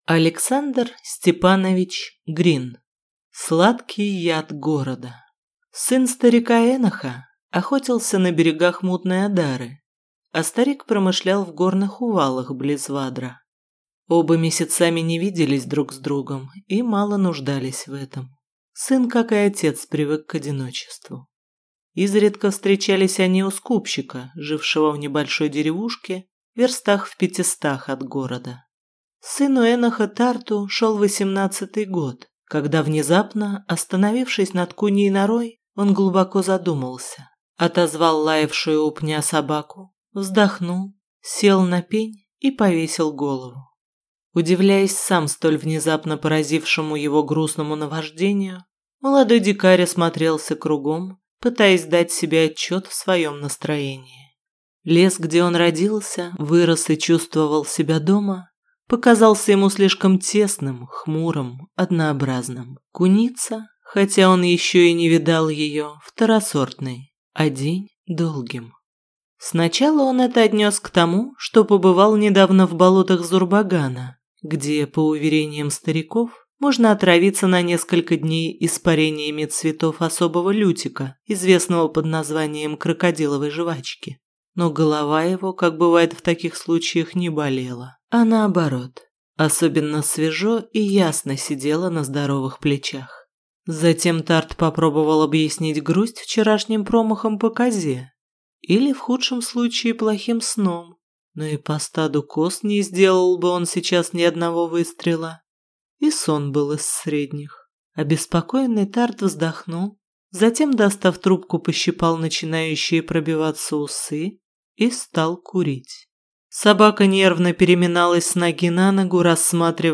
Аудиокнига Сладкий яд города | Библиотека аудиокниг